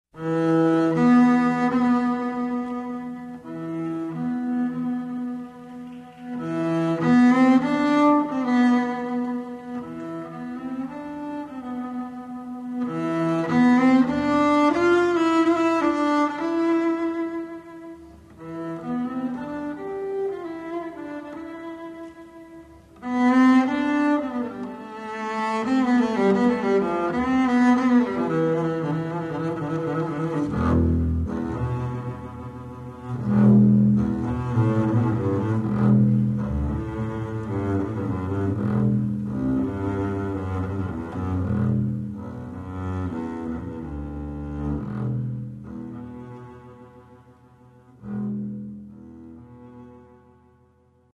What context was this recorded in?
Please note: These samples are not of CD quality.